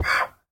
donkey